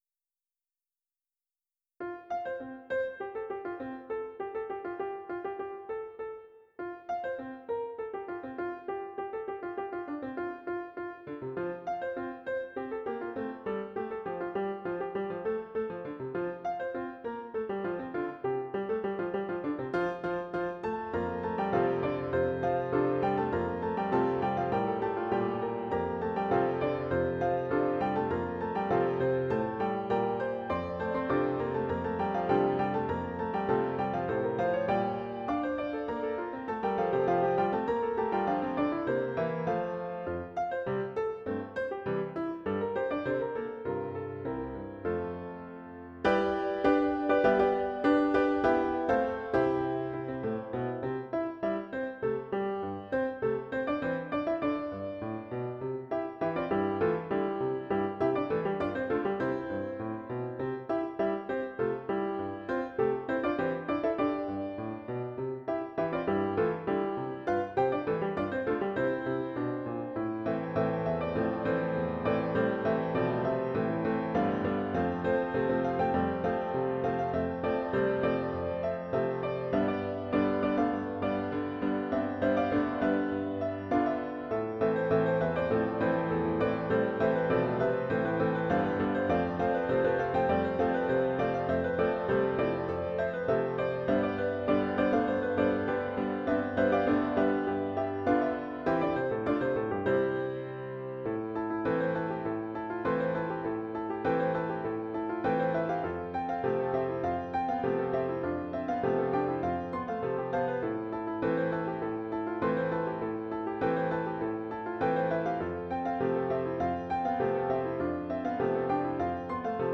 Title Ticklish Spot Opus # 340 Year 2006 Duration 00:03:05 Self-Rating 5 Description Tune inspired by ragtime. Title inspired by my wife. mp3 download wav download Files: wav mp3 Tags: Duet, Piano Plays: 2009 Likes: 0